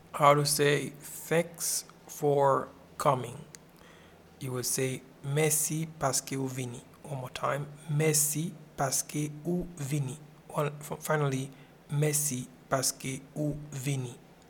Pronunciation and Transcript:
Thanks-for-coming-in-Haitian-Creole-Mesi-paske-ou-vini.mp3